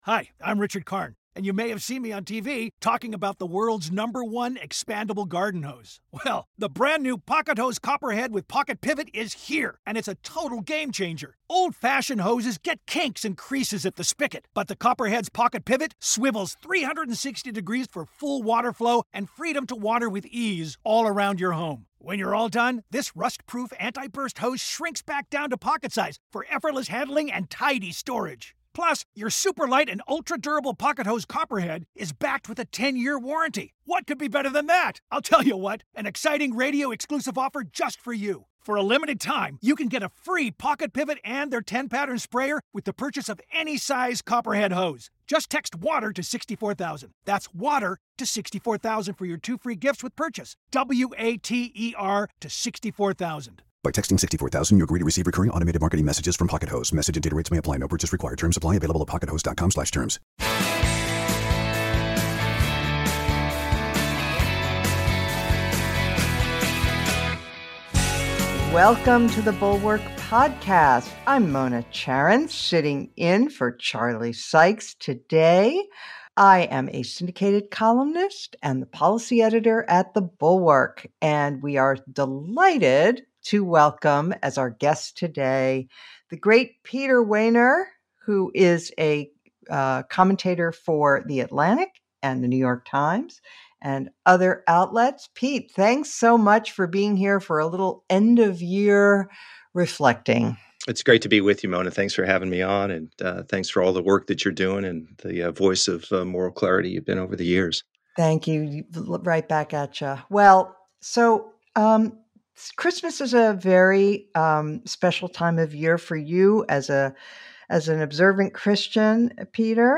Peter Wehner joins guest host Mona Charen on today's episode.